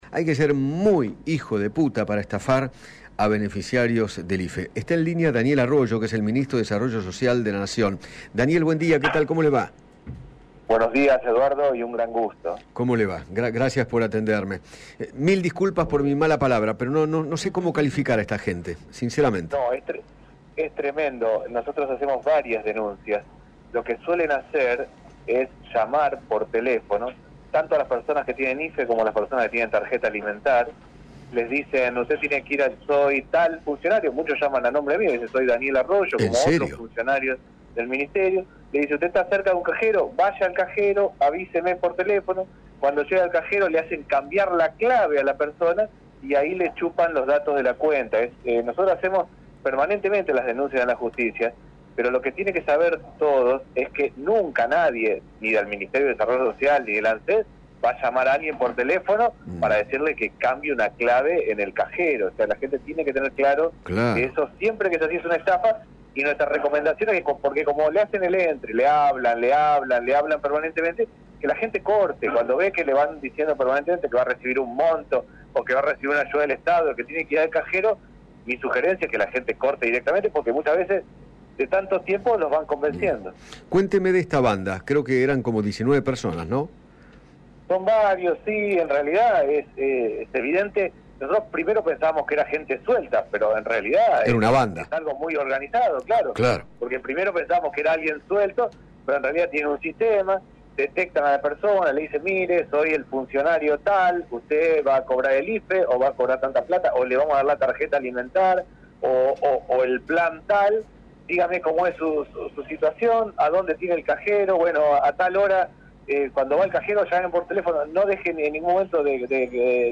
Daniel Arroyo, ministro de Desarrollo, dialogó con Eduardo Feinmann sobre las denuncias por estafas aduciendo al pago de un cuarto IFE, el cual el gobierno confirmó que no dará y explicó cómo operan las bandas fraudulentas.